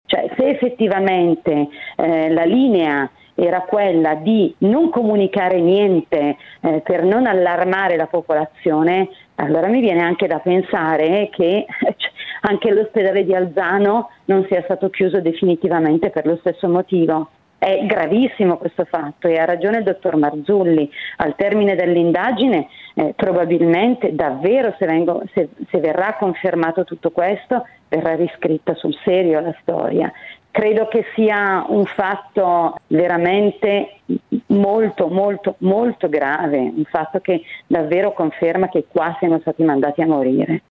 Così ha commentato ai nostri microfoni